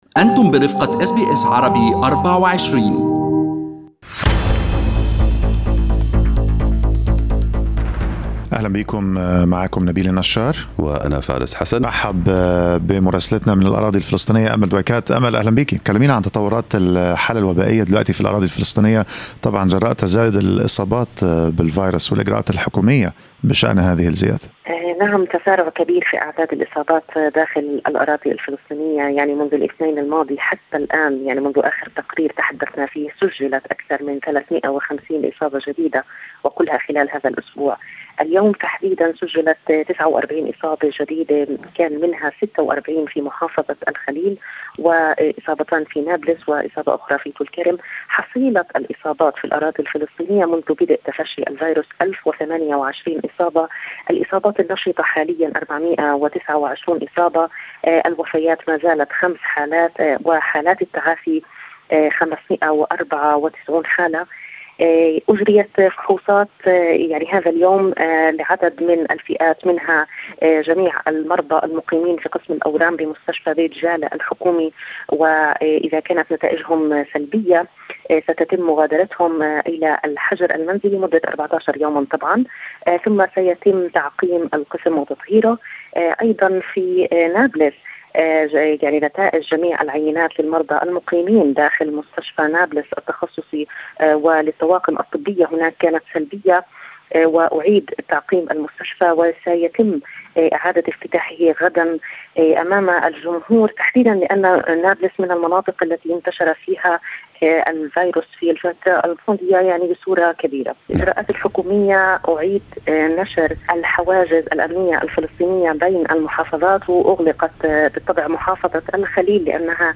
مراسلتنا في الأراضي الفلسطينية تطلعنا في هذا البودكاست على آخر تطورات الحالة الوبائية في الأراضي الفلسطينية وطريقة تعامل السلطات معها، وتعرج على تفاصيل دعوة فتح للفلسطينيين للتعبئة رفضاً لقرار اسرائيل ضم أراضي غور الأردن.